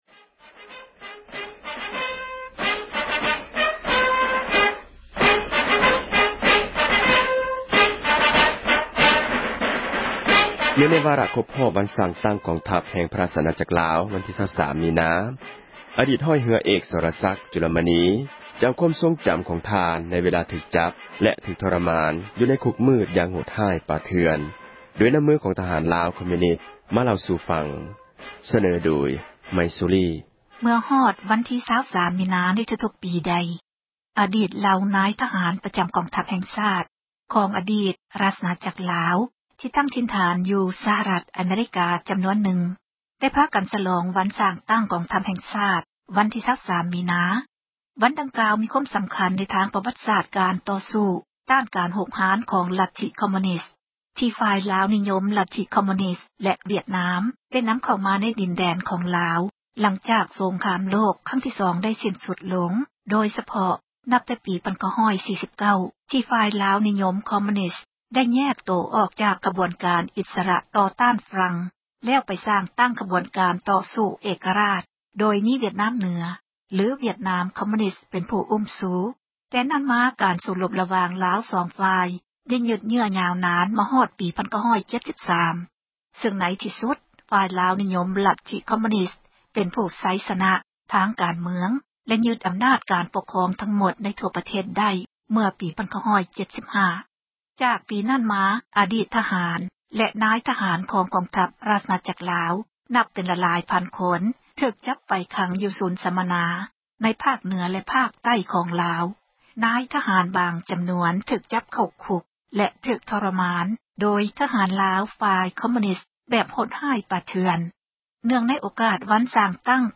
ສໍາພາດອະດີດນັກໂທດ ການເມືອງ